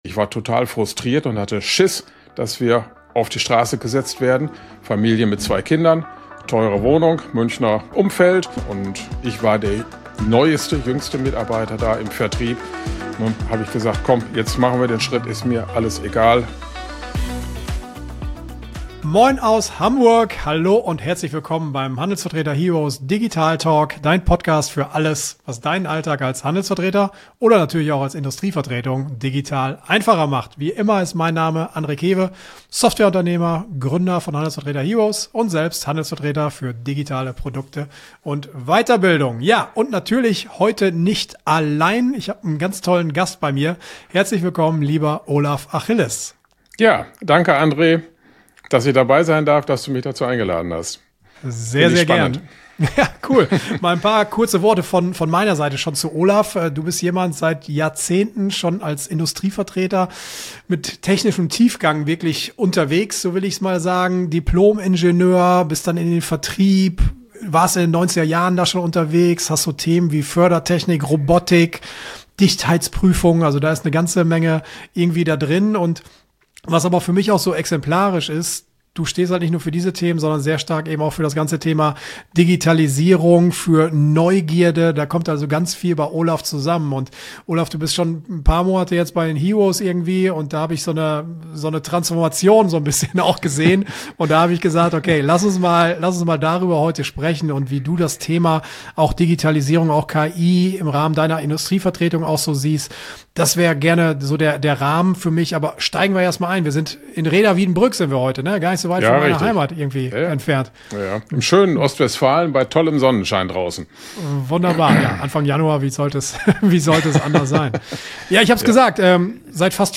Ein Talk voller Lebenserfahrung, Resilienz und echter Aufbruchsstimmung.